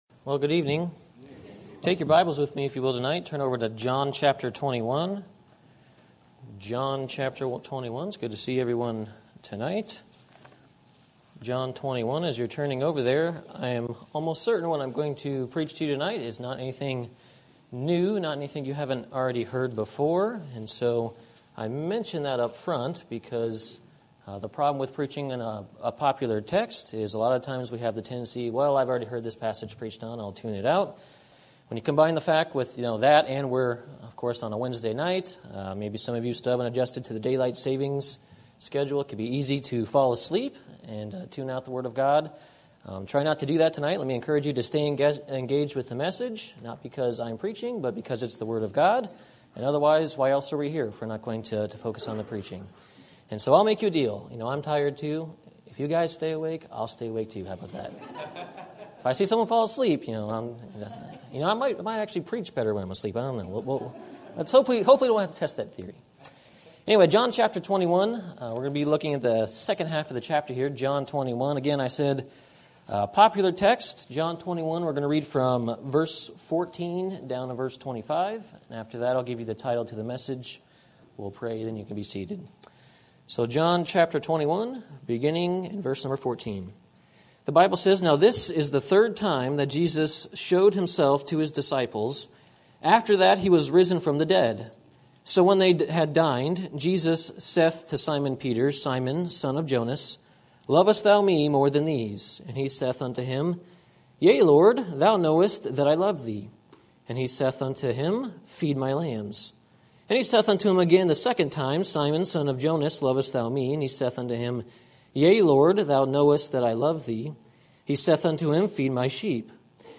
Sermon Topic: General Sermon Type: Service Sermon Audio: Sermon download: Download (21.19 MB) Sermon Tags: John Jesus Peter Love